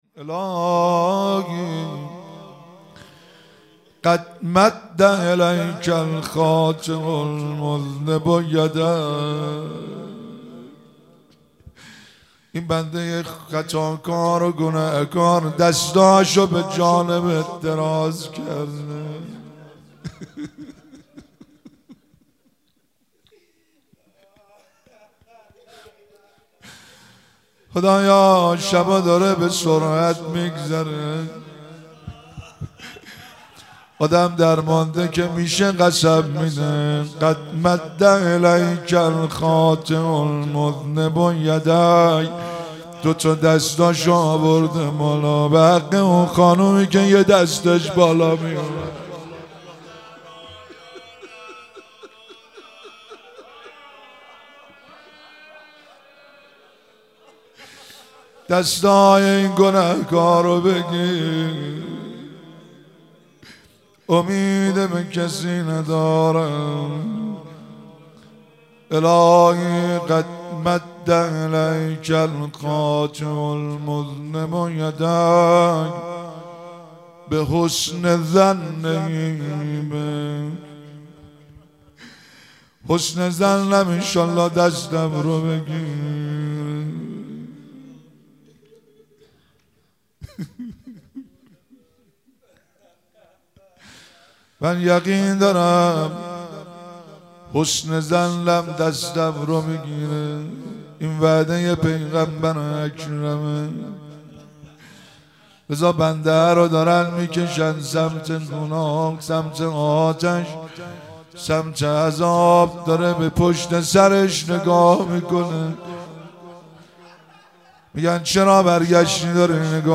مراسم مناجات خوانی شب پنجم ماه رمضان 1444